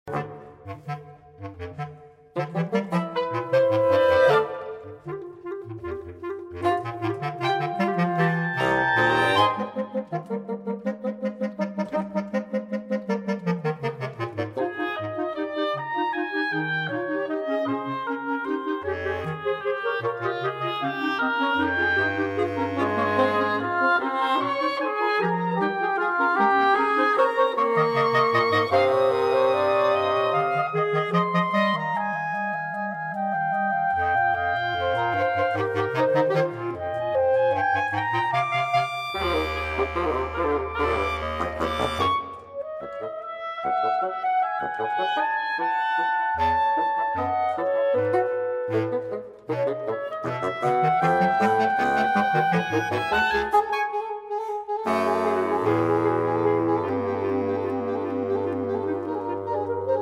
Reed Quintet